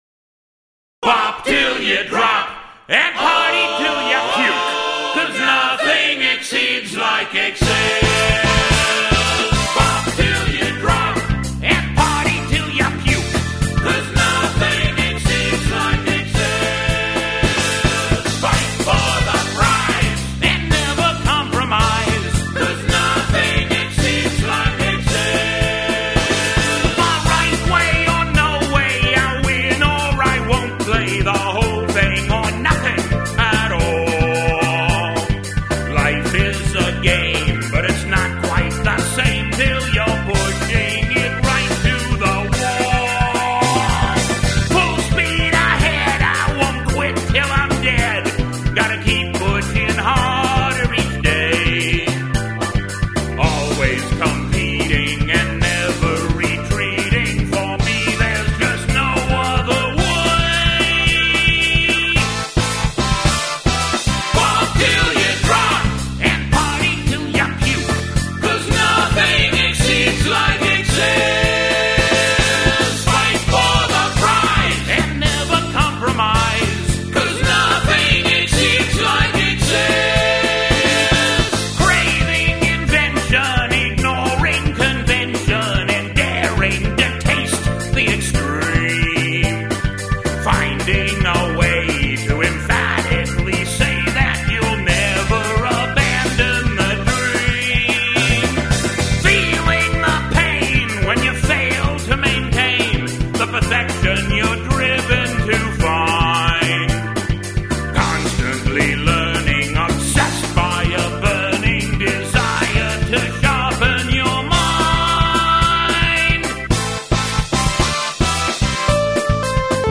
Here it is again, with my version of the solo.